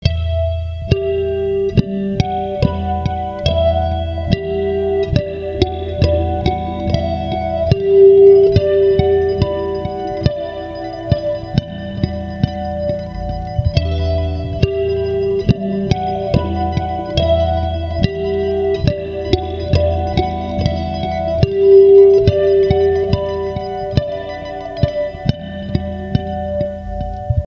Open_oceaan.mp3